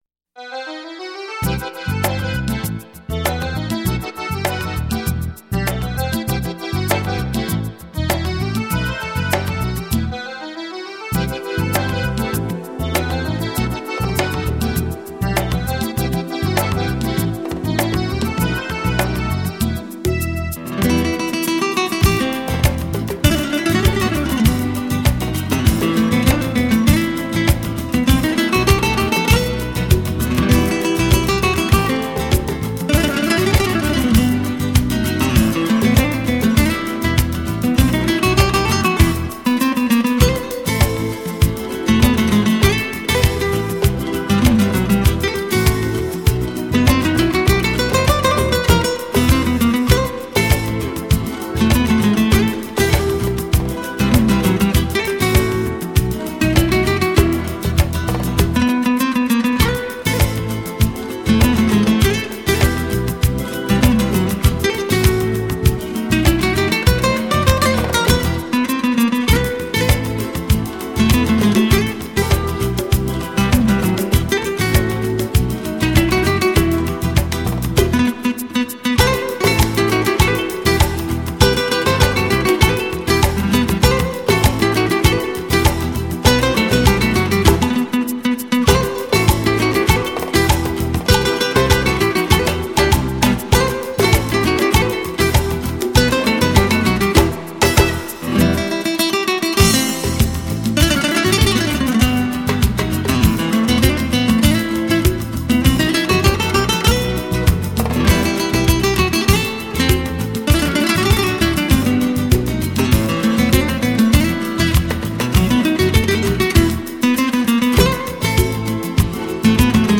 融合了拉丁、吉普赛、爵士的音乐元素而形成他独特的风格，在热情洋溢的弗拉门戈节奏中